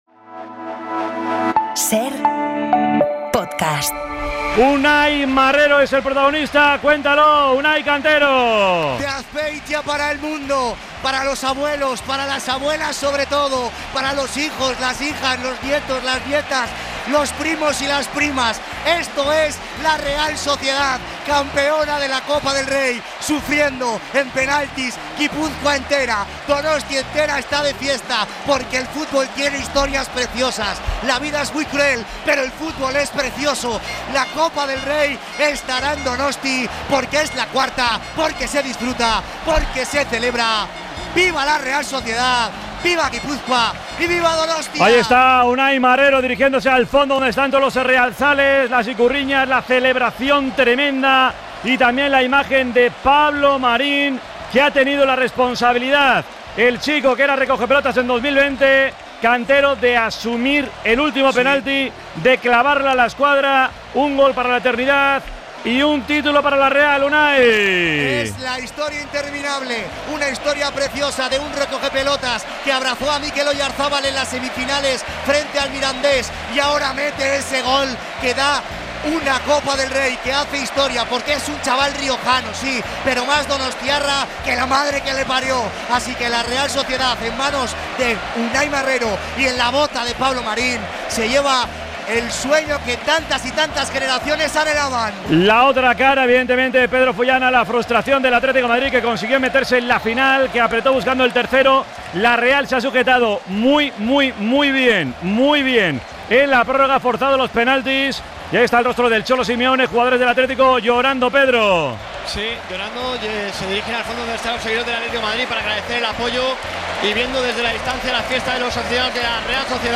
La Real Sociedad supera al Atlético de Madrid en penaltis para hacerse con la Copa del Rey. Escuchamos en la Cadena SER con el programa desde el estadio a los protagonistas de la noche: Odriozola, Marrero, Sergio Gómez, Barrenetxea, Pablo Marín, Kubo, Carlos Soler, Remiro, Oyarzabal... y también al presidente Jokin Aperribay. Además, todo el análisis por parte de los miembros de 'El Sanedrín'.